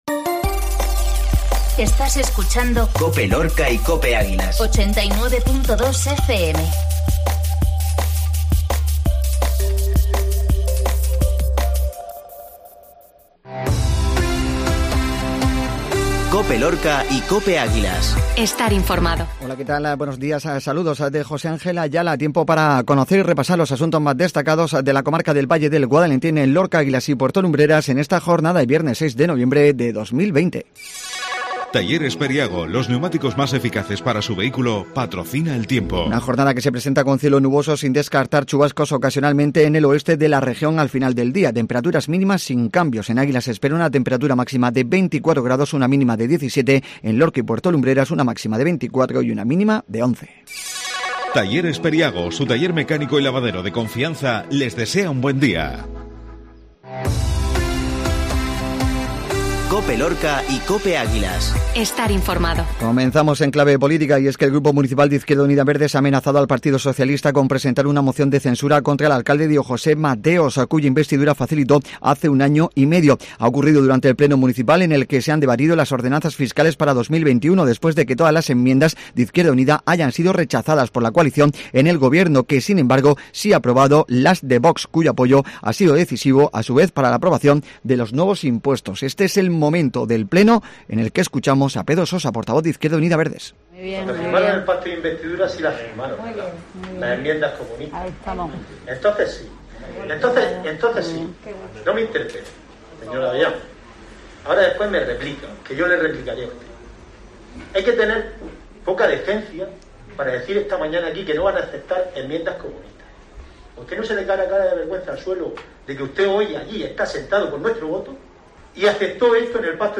INFORMATIVO MATINAL VIERNES